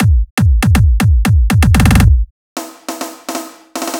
120 BPM Beat Loops Download